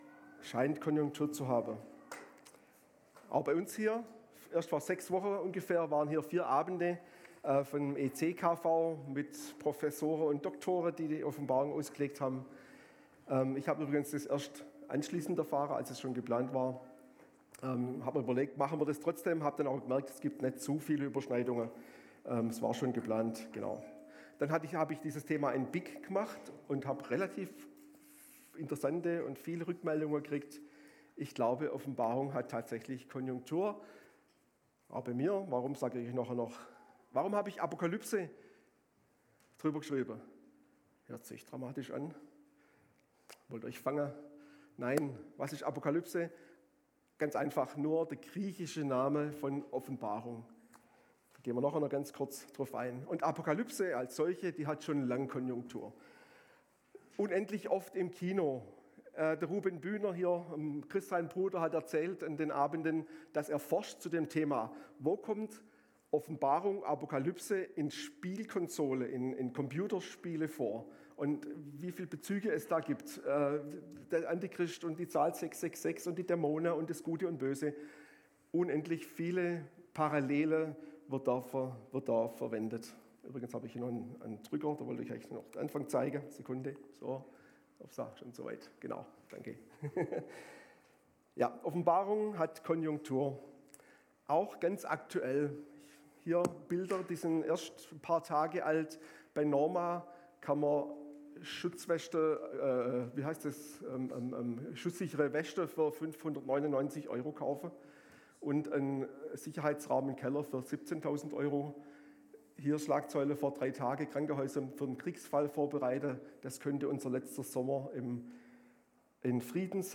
Gottesdienst am 30.03.2025